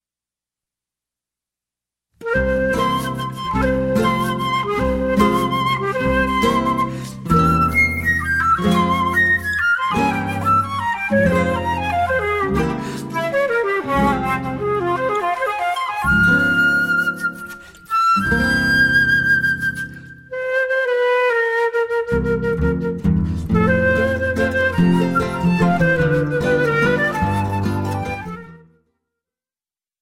Choro ensemble